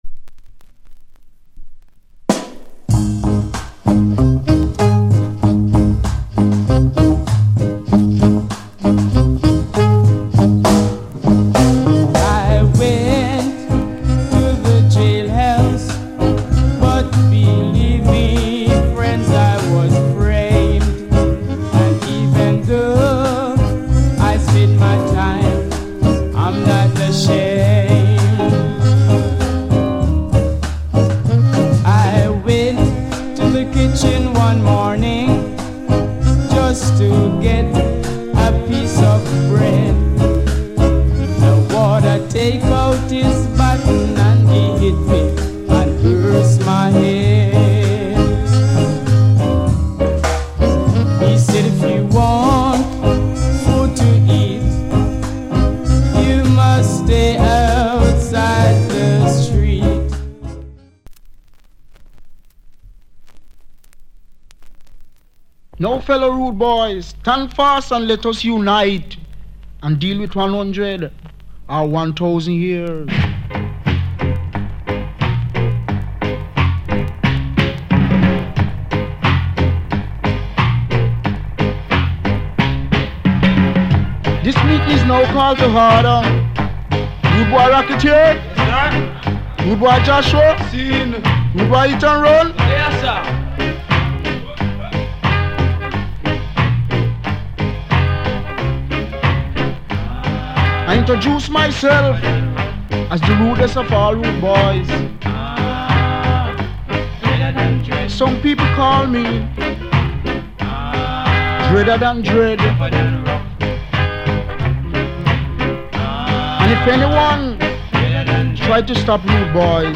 Genre Rock Steady / [A] Male Vocal [B] Male DJ